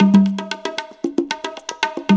CONGA BEAT35.wav